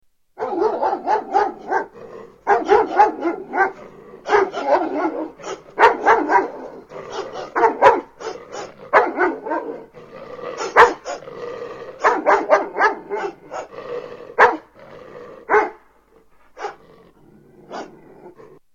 Dog Aggressive Barking
Category: Sound FX   Right: Personal